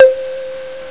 1 channel
bell.mp3